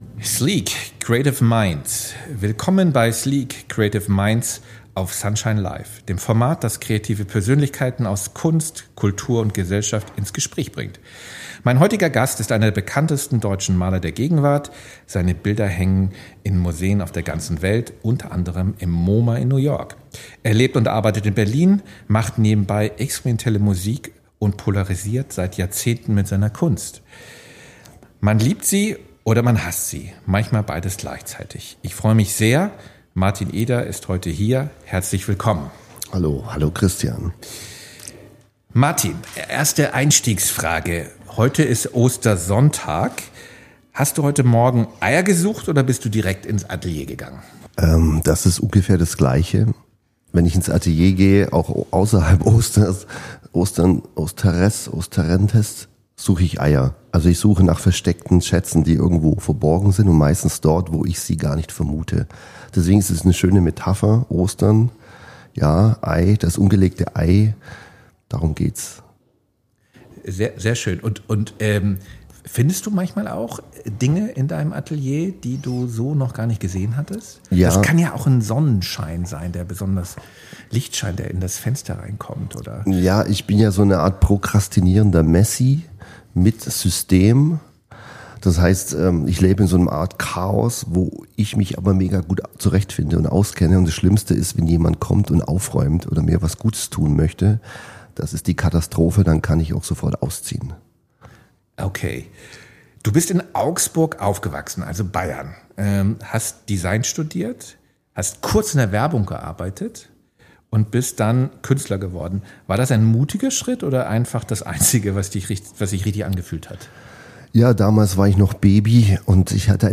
Im Gespräch geht es um seinen Weg aus der kommerziellen Werbewelt in die internationale Kunstszene, um die bewusste Entscheidung für Berlin als Arbeitsort und um eine Bildsprache, die zugleich verstört und verführt – zwischen kitschiger Oberfläche und abgründiger Tiefe. Ein persönliches Gespräch über kreative Disziplin, den Mut zur Irritation, den langen Atem künstlerischer Entwicklung und das Spannungsfeld zwischen Kontrolle und Chaos, das Eders Werk bis heute prägt.